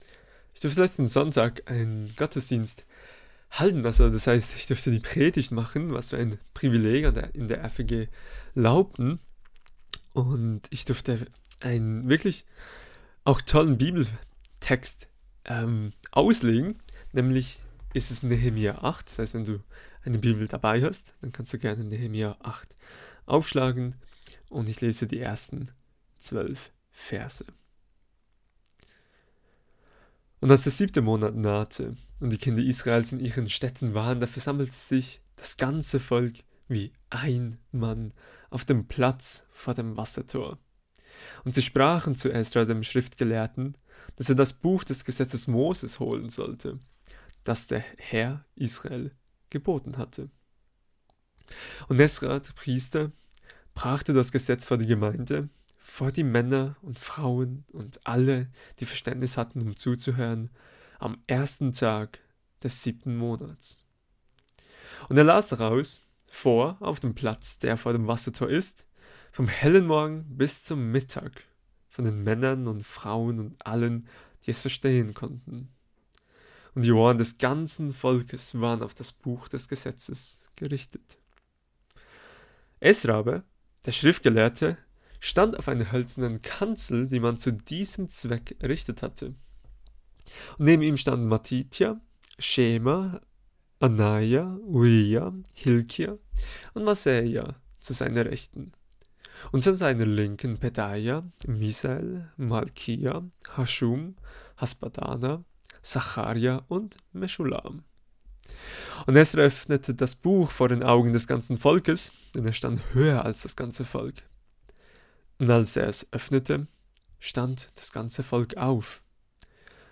Kategorie: Gottesdienst